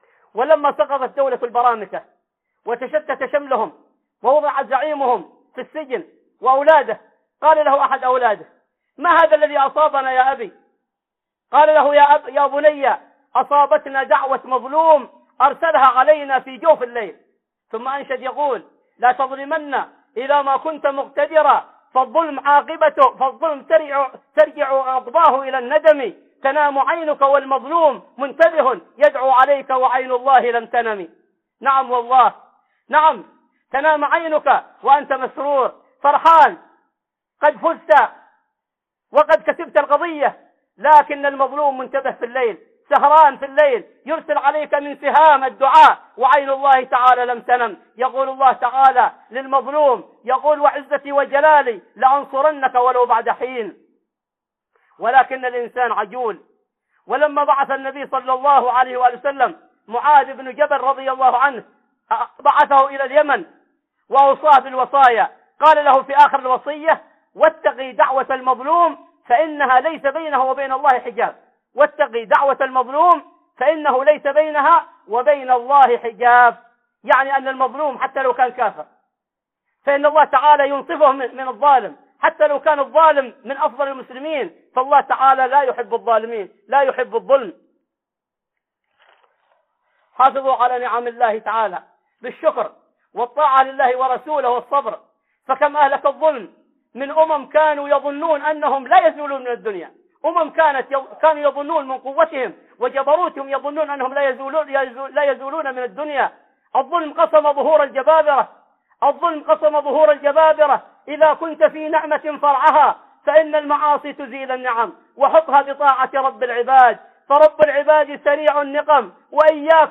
دعوة المظلوم وعاقبة الظالم في الدنيا - خطب